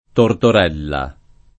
Tortorella [ tortor $ lla ]